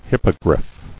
[hip·po·griff]